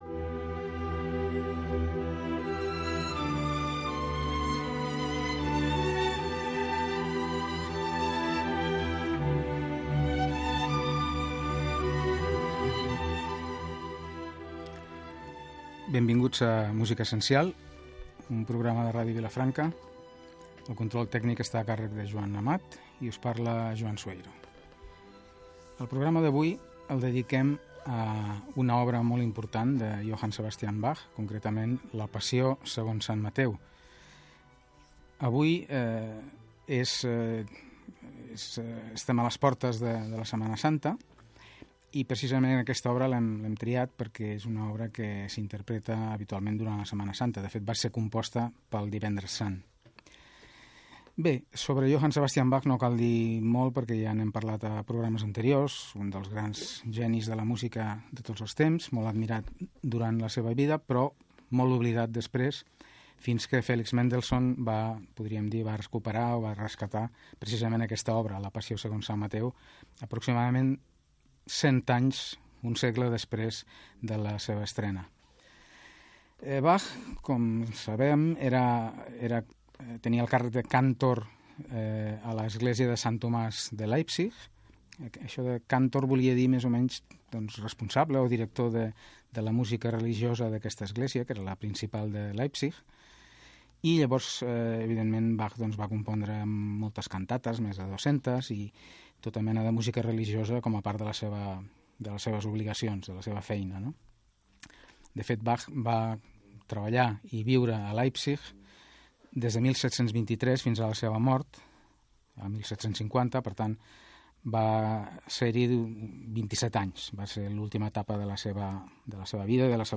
Programa musical